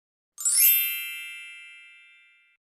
Bell Transition Sound Effect Free Download
Bell Transition